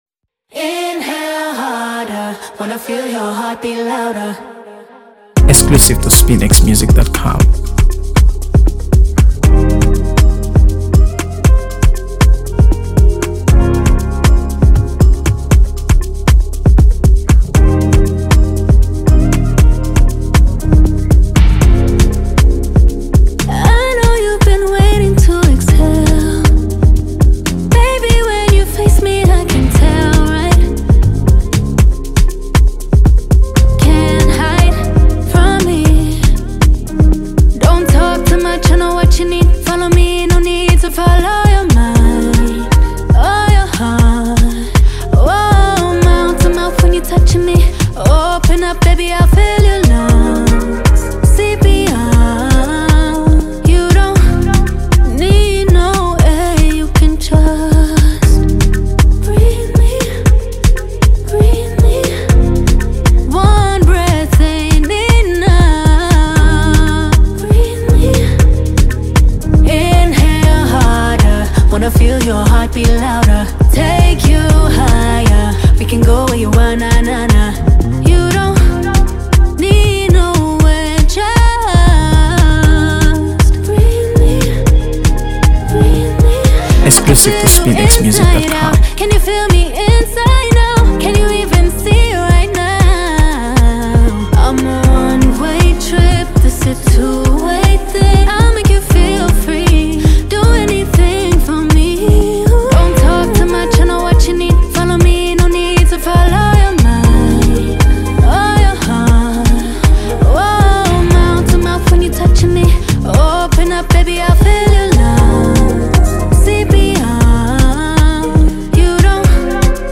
AfroBeats | AfroBeats songs
soulful, seductive tunes